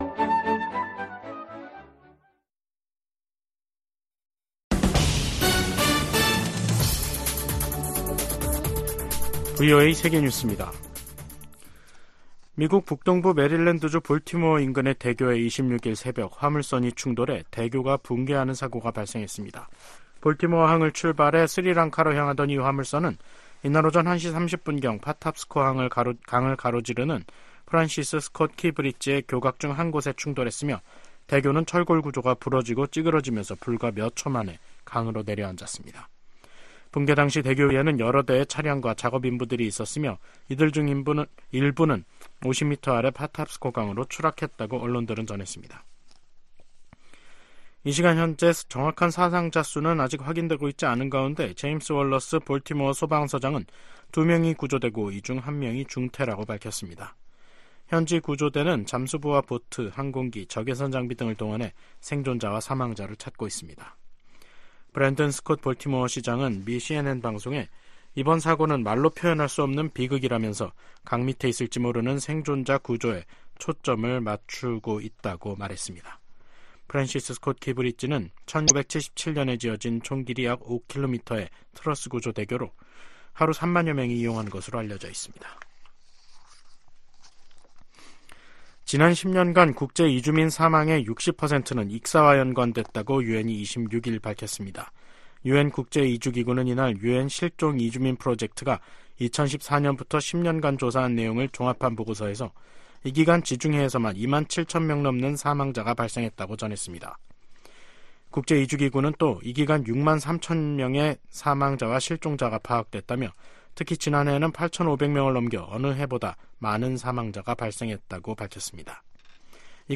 VOA 한국어 간판 뉴스 프로그램 '뉴스 투데이', 2024년 3월 26일 3부 방송입니다. 조 바이든 미국 대통령이 서명한 2024회계연도 예산안에 북한 관련 지출은 인권 증진, 대북 방송, 북한 내 미군 유해 관련 활동이 포함됐습니다. 미 국방부가 일본 자위대의 통합작전사령부 창설 계획에 대한 지지를 표명했습니다. 남북한이 다음달 군사정찰위성 2호기를 쏠 예정으로, 우주경쟁에 돌입하는 양상입니다.